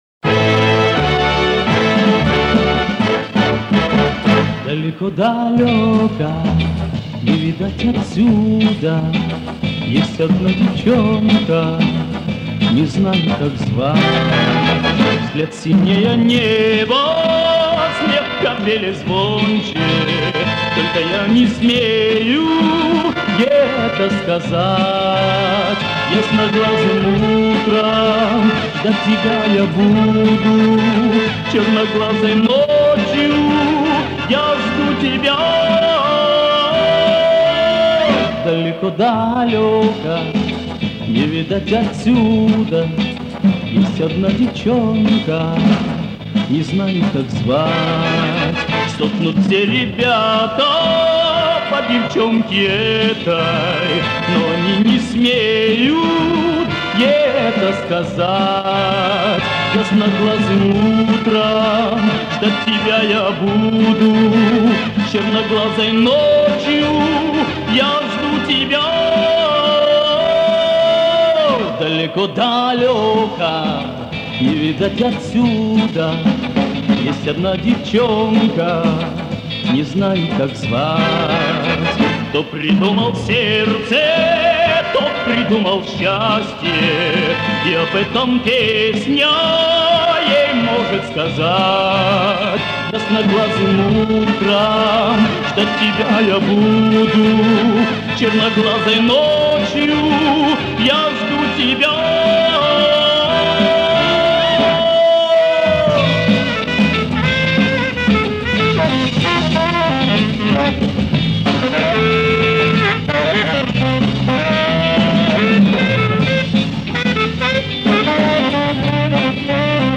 микро провалы звука